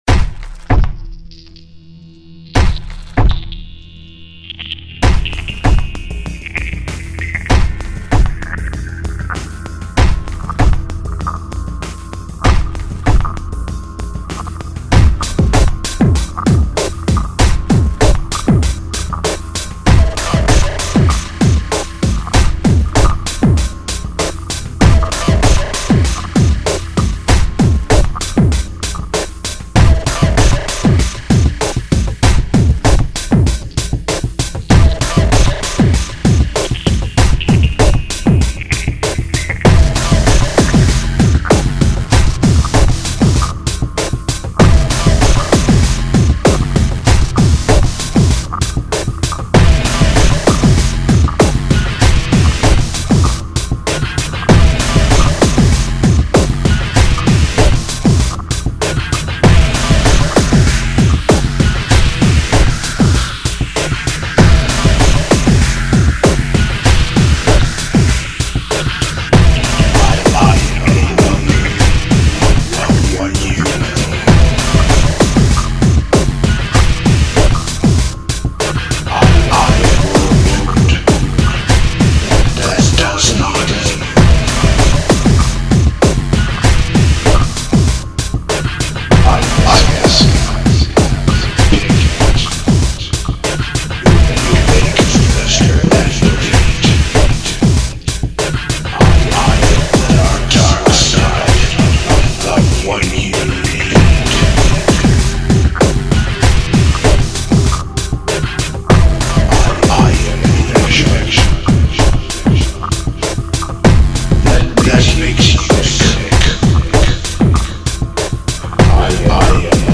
Dark Industrial Spooky Metal Gothic Electronica